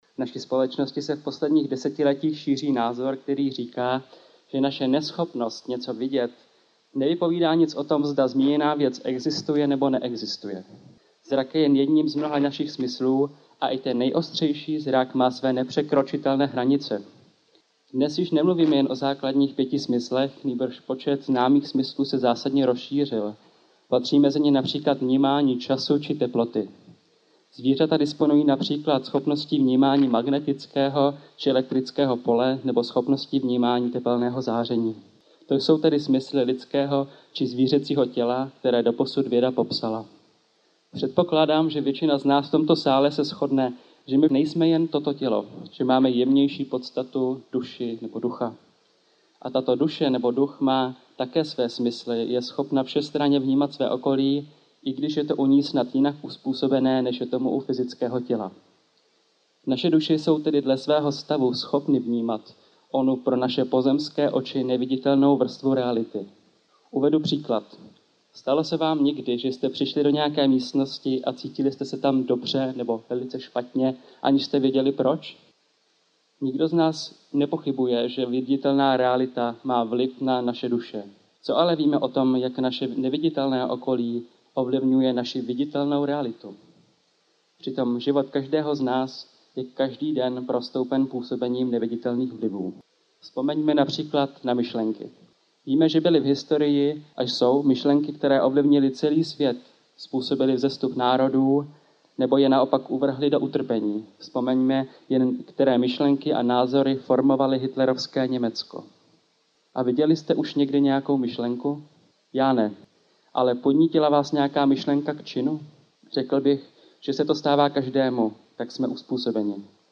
AudioKniha ke stažení, 2 x mp3, délka 1 hod. 8 min., velikost 62,1 MB, česky